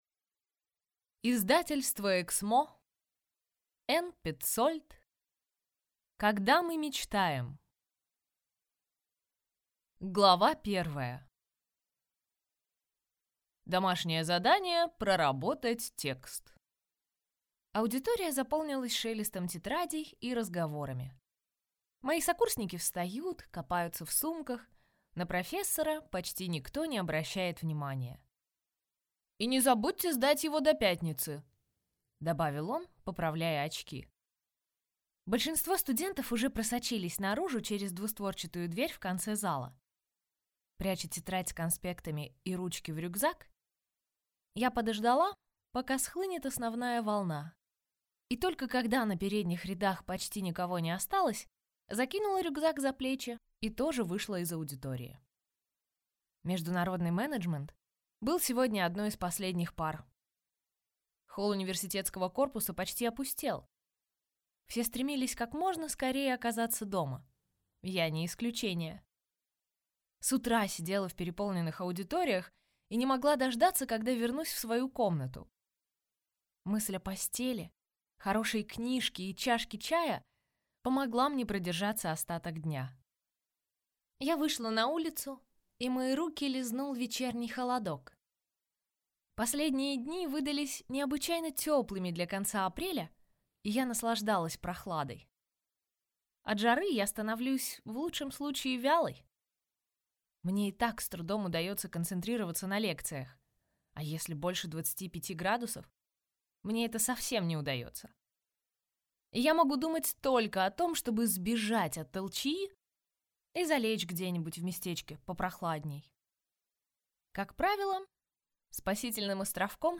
Аудиокнига Когда мы мечтаем | Библиотека аудиокниг